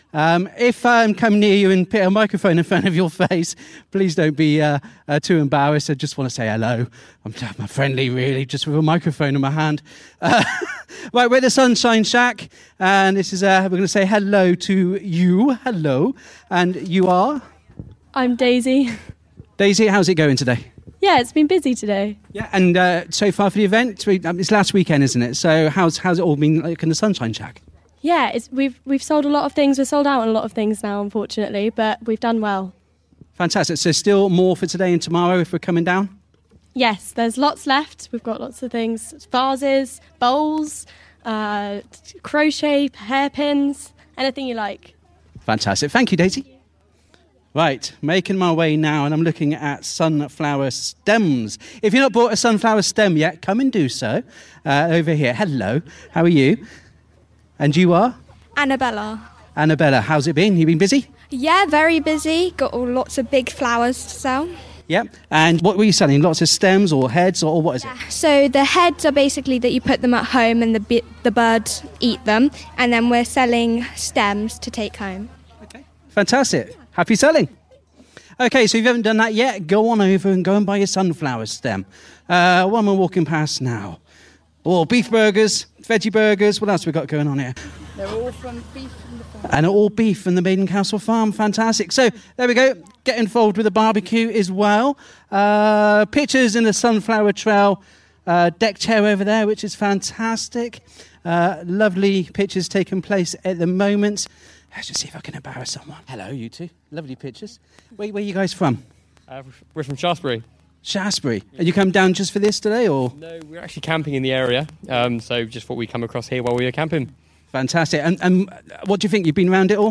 One of Dorchester’s most popular attractions is the Sunflower Trail at Maiden Castle Farm which included, on Saturday 16th August, the Sunflower Stride, a fun run through the sunflowers of either 10k or 5k depending on ability. The aim of the runs was to raise funds for the Dorset & Somerset Air Ambulance.